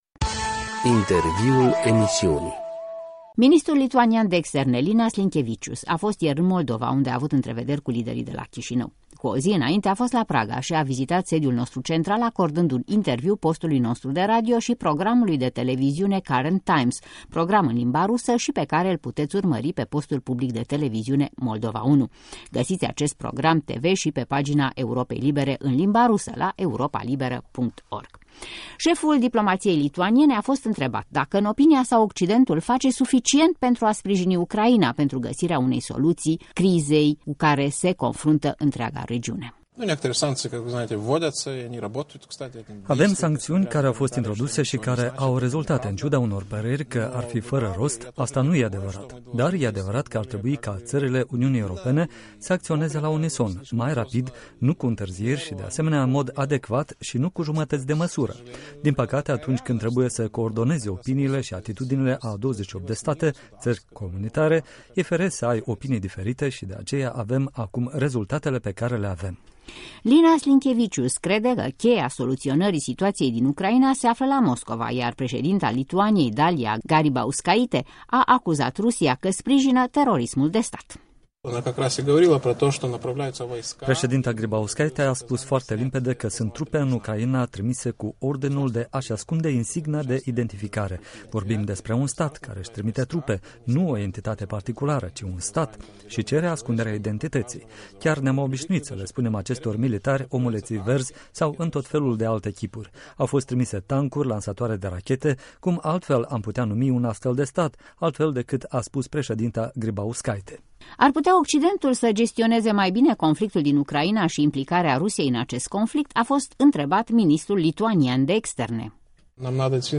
Interviuri la Europa Liberă: Linas Linkevicius, ministrul de externe al Litauniei